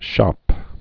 (shōp, shŏp)